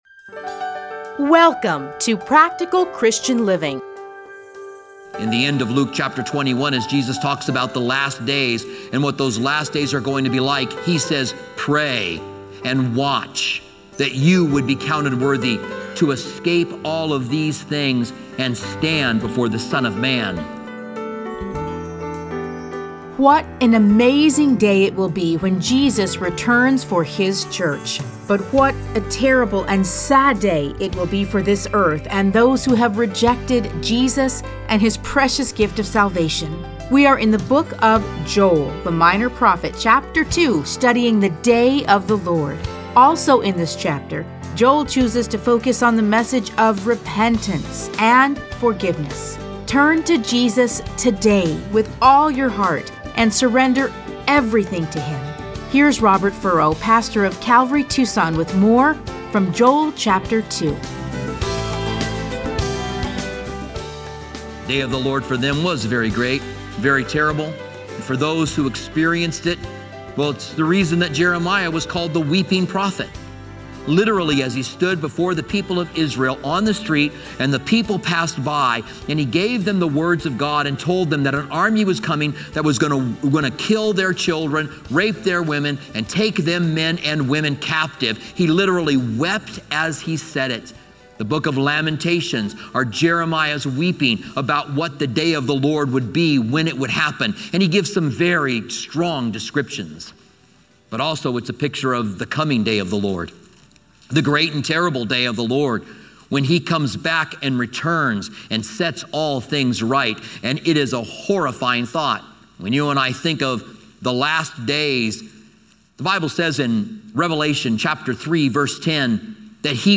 Listen to a teaching from Joel 2:1-17, 21-27.